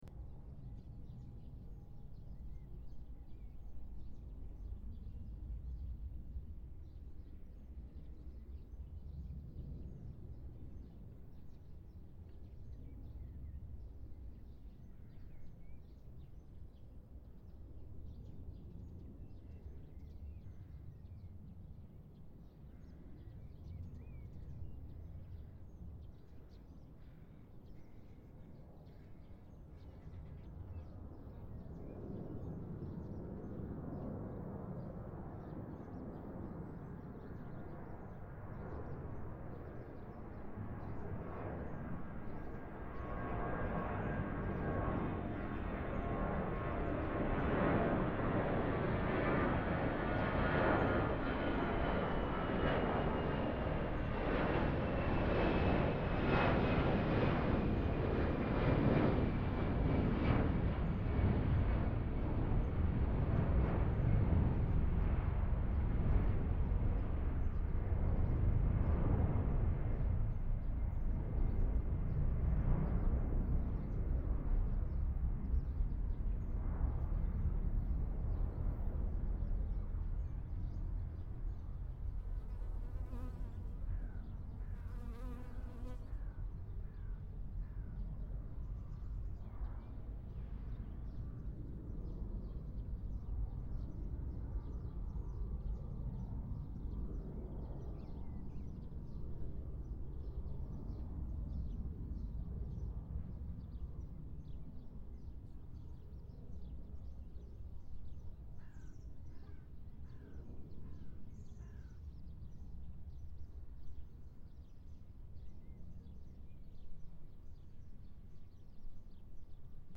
Auralization of atmospheric turbulence-induced amplitude fluctuations in aircraft flyover sound based on a semi-empirical model | Acta Acustica
02_measurement_high_turbulence.mp3 (7